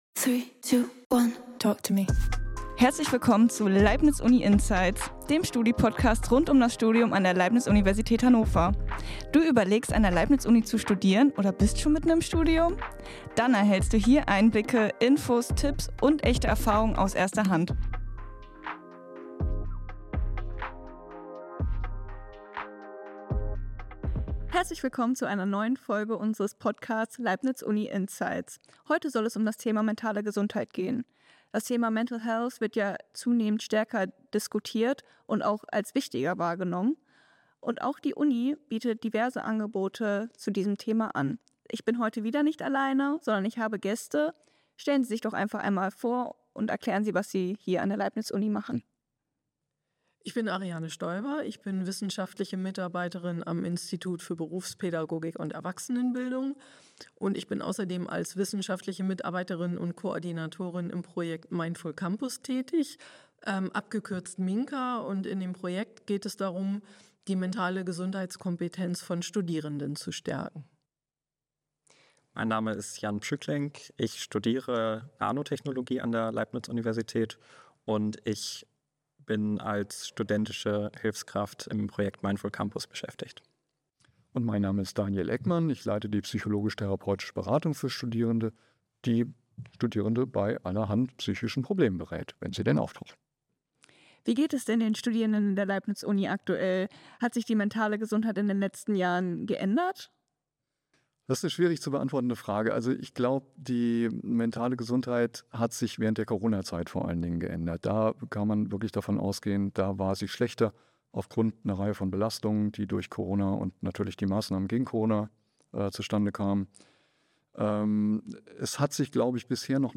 mit drei Expert*innen von der LUH